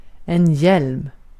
Uttal
Uttal Okänd accent: IPA: /jɛlm/ Ordet hittades på dessa språk: svenska Översättning 1. kask 2. miğfer Artikel: en .